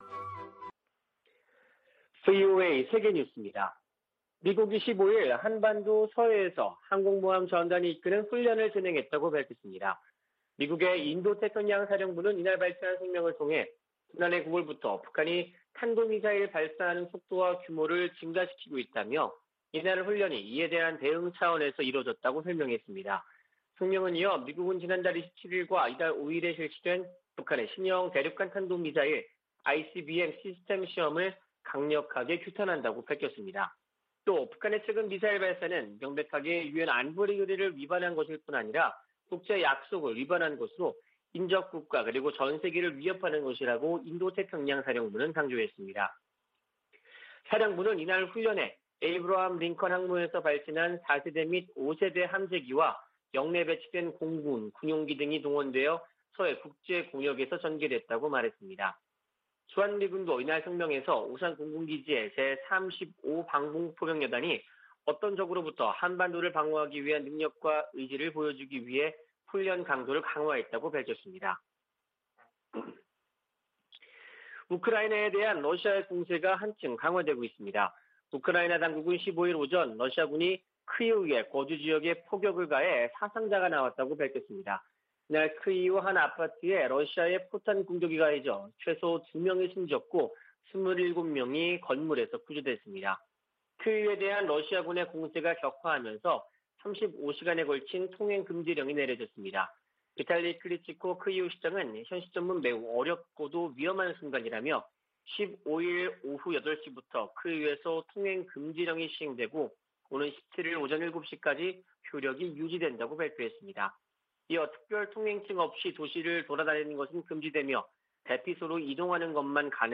VOA 한국어 아침 뉴스 프로그램 '워싱턴 뉴스 광장' 2022년 3월 16일 방송입니다. 북한이 이동식발사대(TEL)에서 미사일을 쏠 때 사용하는 콘크리트 토대를 순안공항에 증설한 정황이 포착됐습니다. 백악관은 북한의 신형 ICBM 발사가 임박했다는 보도와 관련해, 예단하지 않겠다고 밝혔습니다. 백악관 국가안보보좌관이 중국 고위 당국자와 만났습니다.